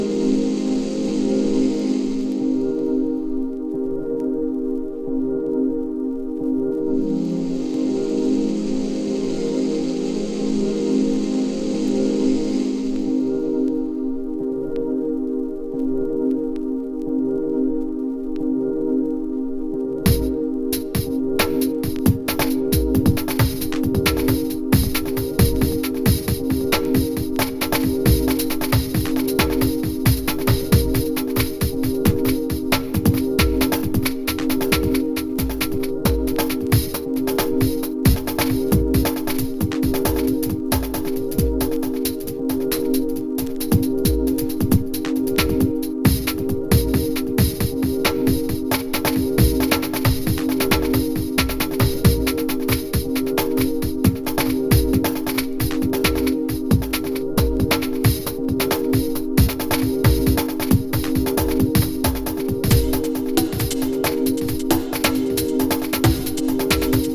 エスニック/トライバルなBreak Beats〜Atmosphericなダウンテンポまで収録。